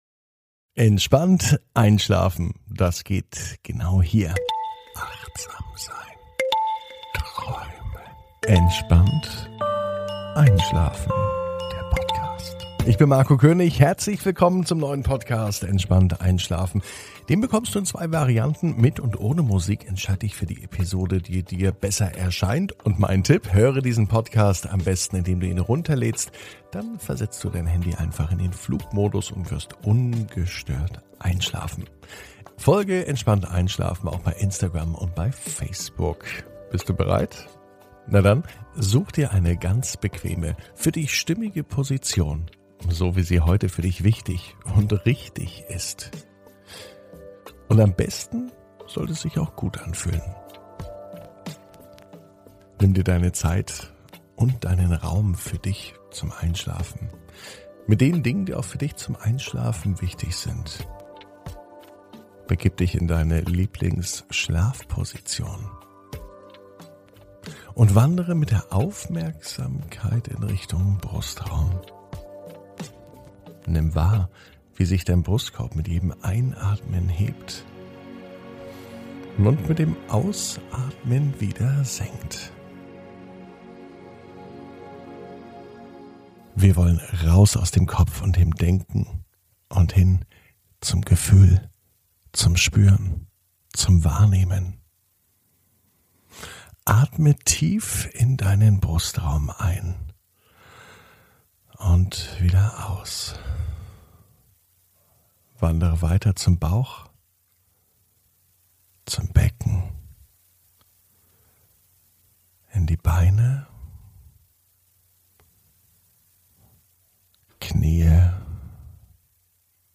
(Ohne Musik) Entspannt einschlafen am Samstag, 22.05.21 ~ Entspannt einschlafen - Meditation & Achtsamkeit für die Nacht Podcast